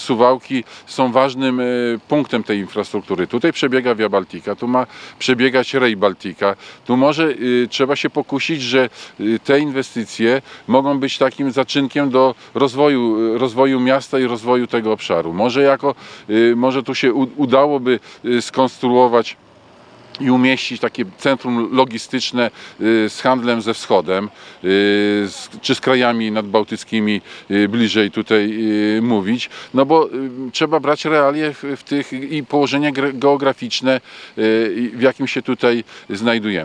podczas konferencji prasowej w Suwałkach